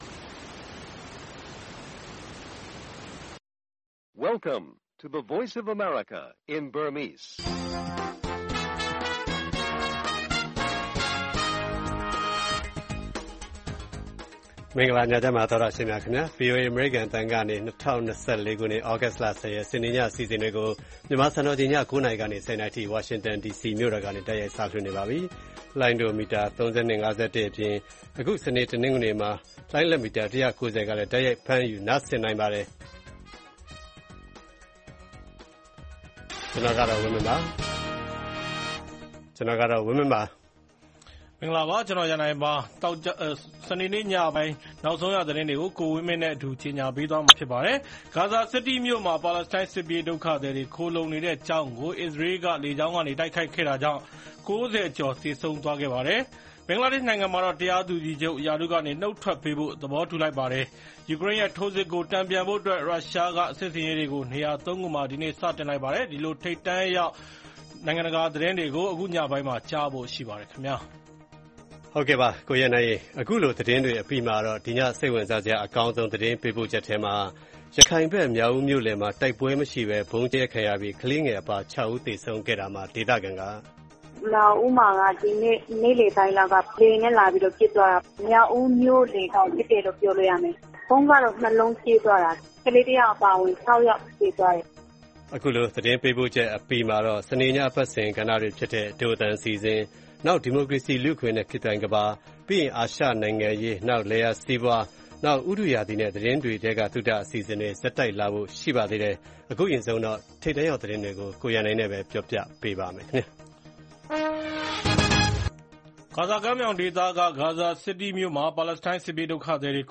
မြောက်ဦးမြို့လယ် ဗုံးကြဲခံရမှု ၆ ယောက်သေဆုံး၊ ယူကရိန်းထိုးစစ် တန်ပြန်နိုင်ဖို့ ရုရှားပြင်ဆင်၊ ဘင်္ဂလားဒေရှ့် တရားသူကြီးချုပ် ရာထူးကဆင်းပေး၊ ပါရီအိုလံပစ်ပြီးဖို့ တရက်လို ပွဲစဉ်များ စတဲ့သတင်းတွေနဲ့ အပတ်စဉ်ကဏ္ဍတွေ တင်ဆက်ထားပါတယ်။